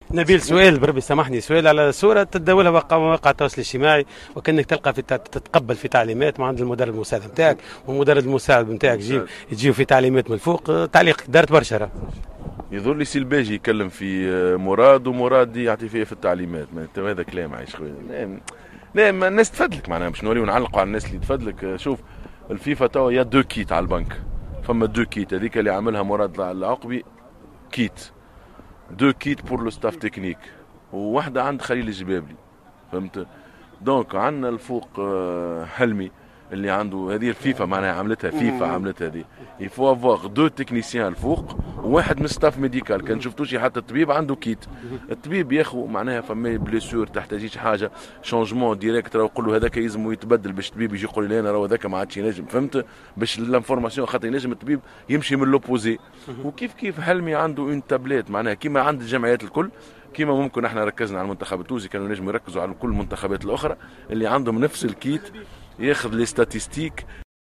نبيل معلول : مدرب المنتخب الوطني تحميل المشاركة علي فيديو